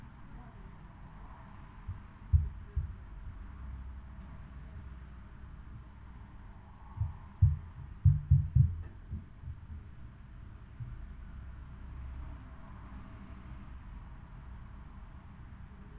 Die "leiseren" Trittschallereignisse
Man sieht auch bei dieser Pegelmessung, wieviel Energie im tieferen Frequenzbereich vorhanden ist.